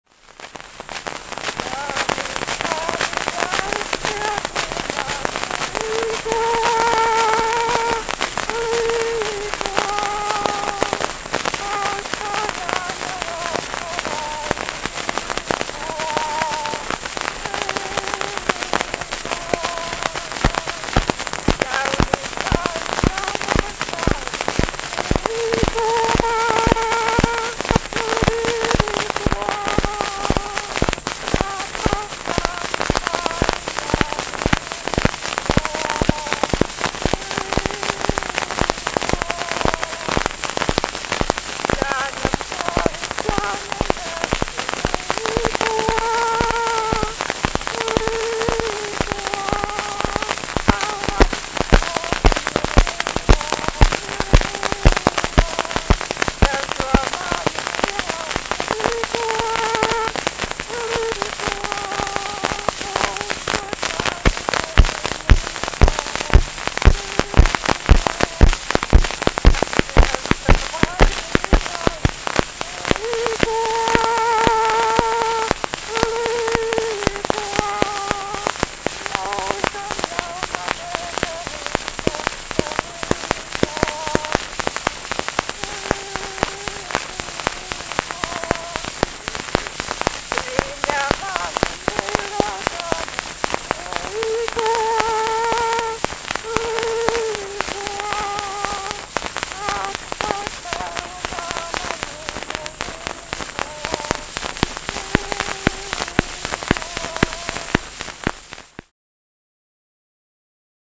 Latviešu valodas dialektu skaņu pieraksti : vaska ruļļi
Fonogrāfa ieraksti
Latviešu valodas dialekti